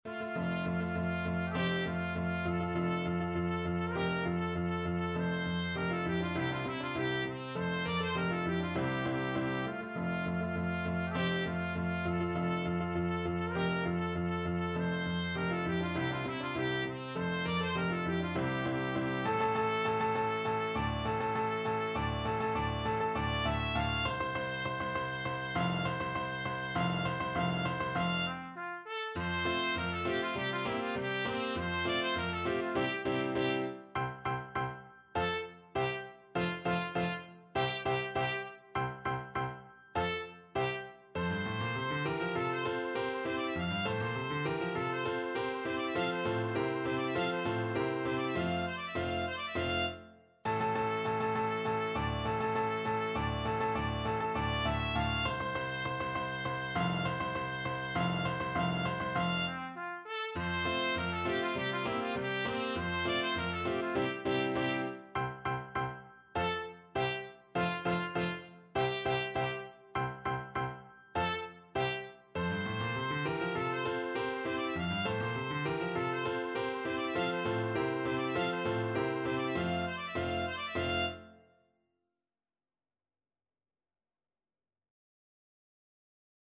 Classical Beethoven, Ludwig van Yorckscher March (2 Marches for Military Band, WoO 18, No. 1) Trumpet version
Trumpet
F major (Sounding Pitch) G major (Trumpet in Bb) (View more F major Music for Trumpet )
2/2 (View more 2/2 Music)
March = c.100
C5-F6
Classical (View more Classical Trumpet Music)
yorckscher_marsch_TPT.mp3